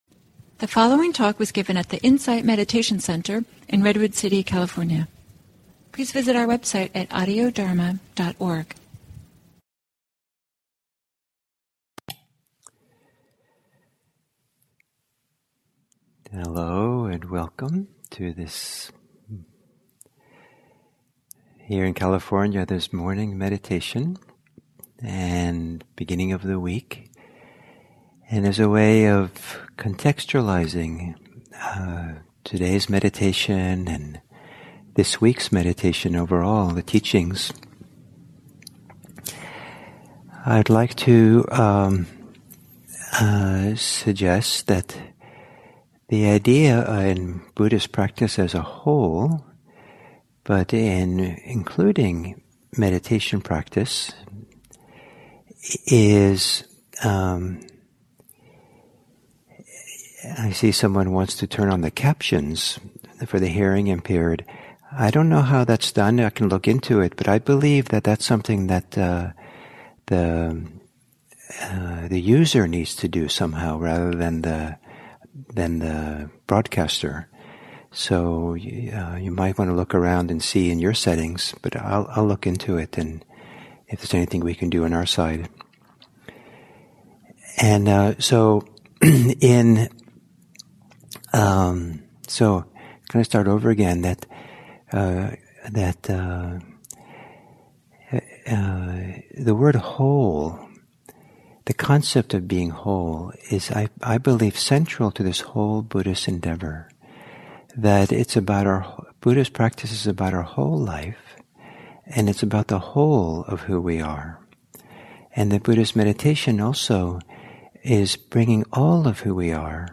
Talks
at the Insight Meditation Center in Redwood City, CA